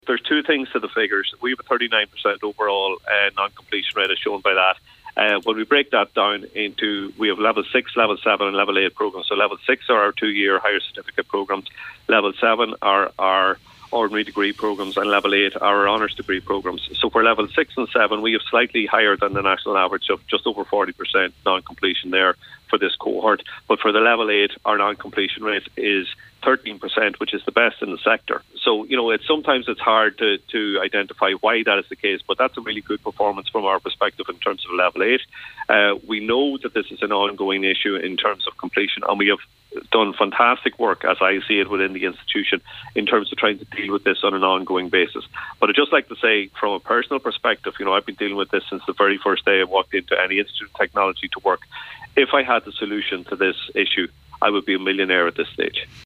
was reacting to the non-completion rates on today’s Nine Til Noon Show.